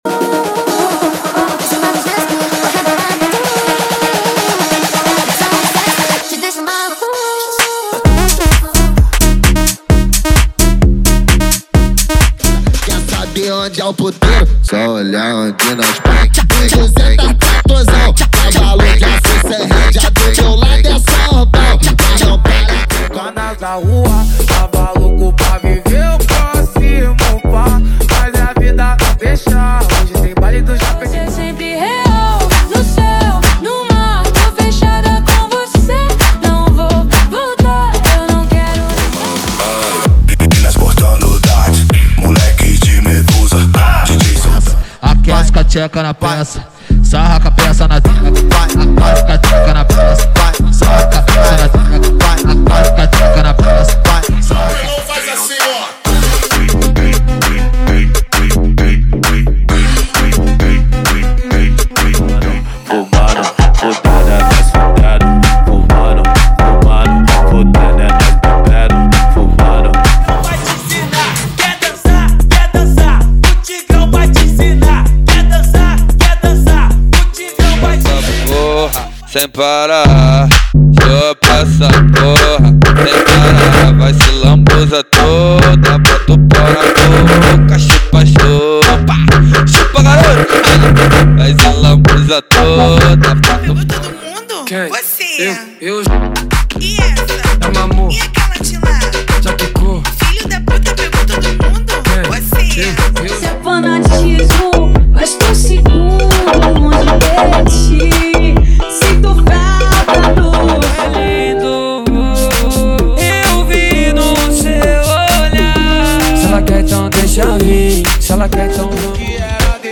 • Sem Vinhetas
• Em Alta Qualidade